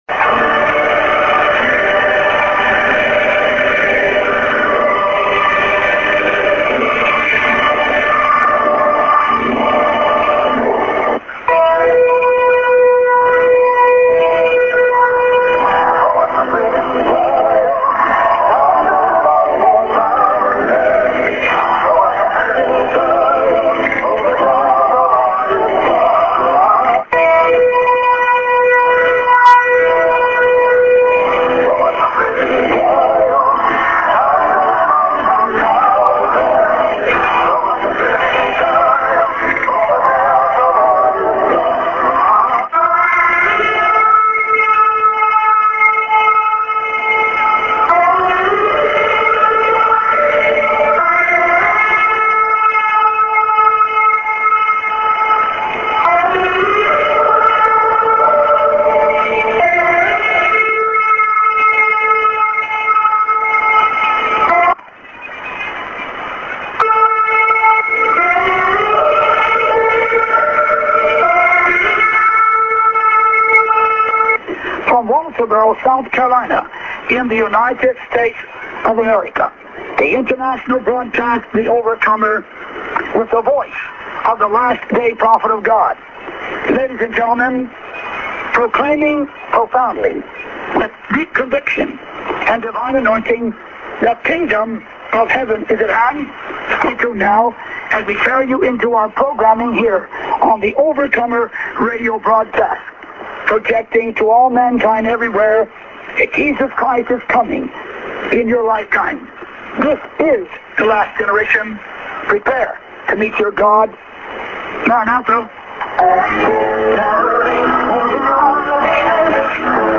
St. music->SJ->ID(man)->